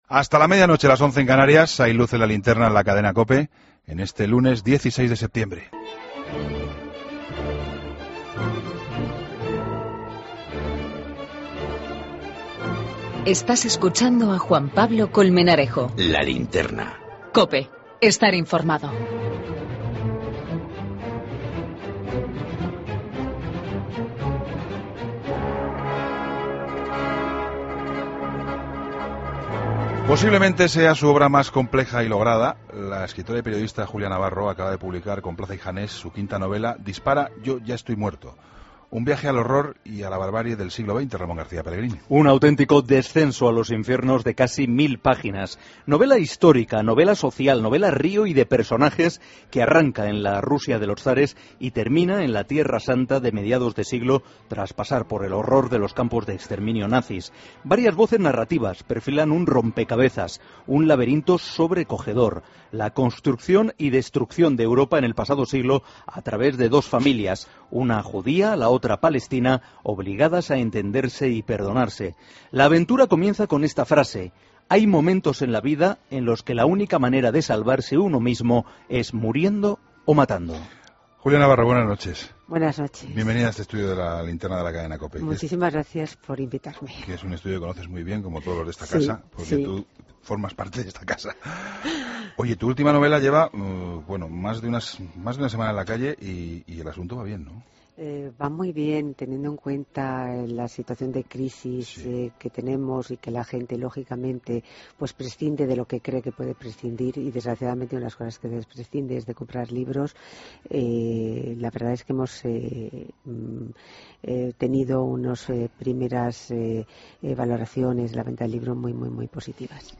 Entrevista Julia Navarro